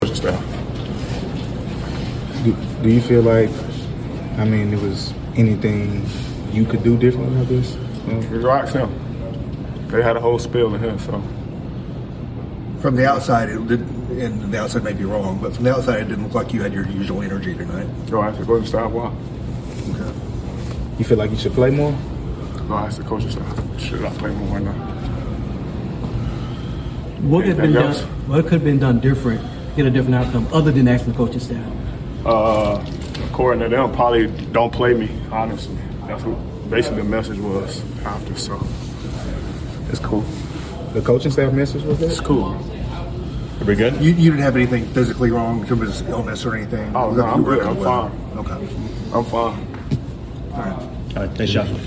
Memphis Grizzlies Guard Ja Morant Postgame Interview after losing to the Los Angeles Lakers at FedExForum.